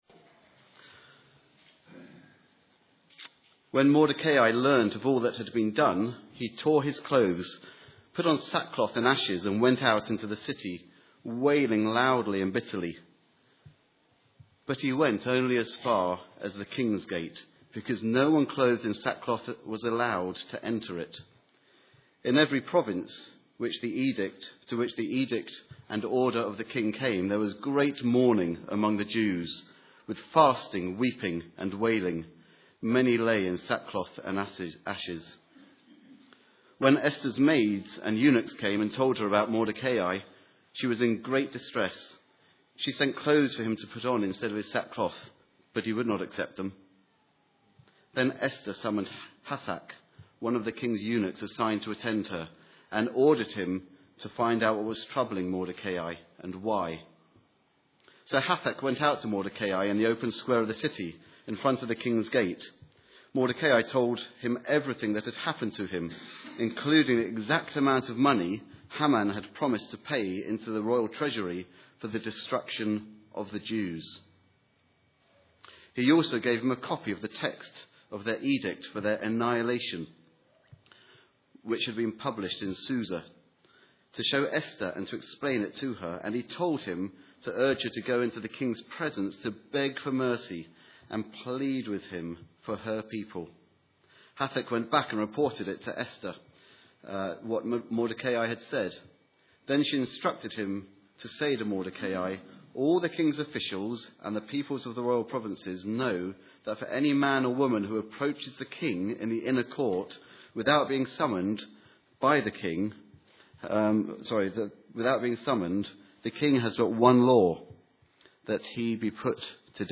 06 February 2011 39:04 Turning Points View Sermons from all the years!